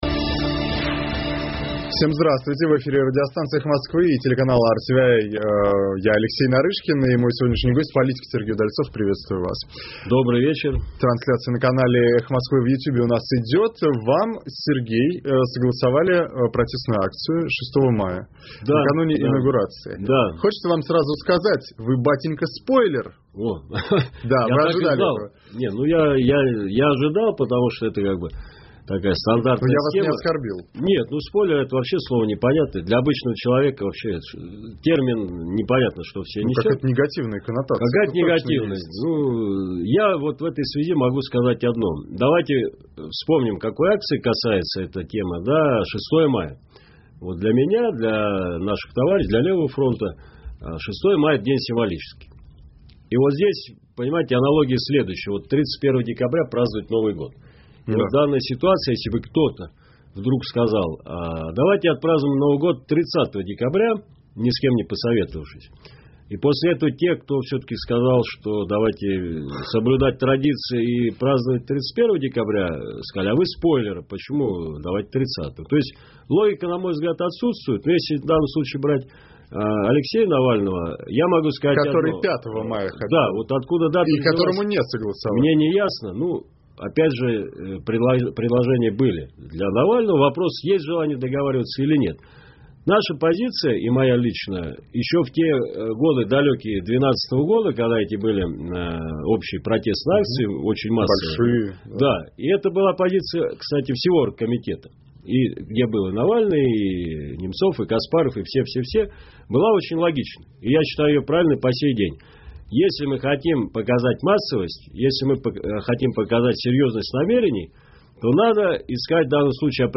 В эфире радиостанция «Эхо Москвы» и телеканал RTVi.